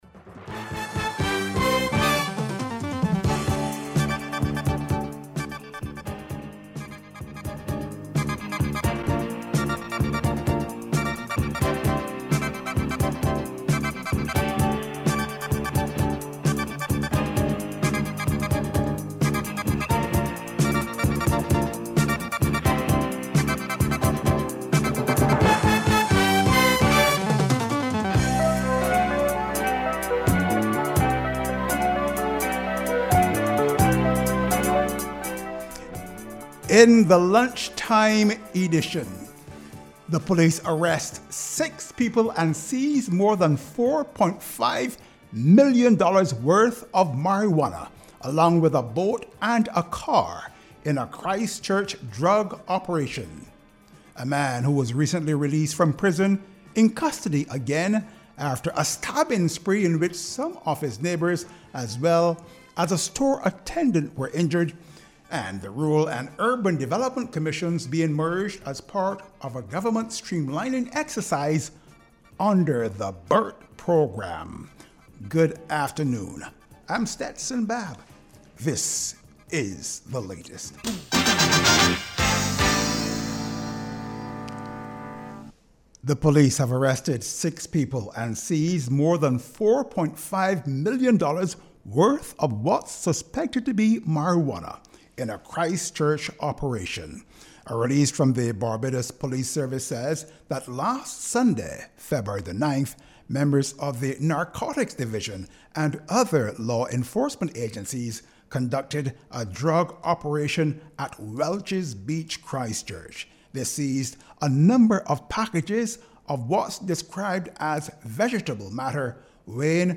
She speaking at an “I am a girl NGO” International Women’s Day workshop.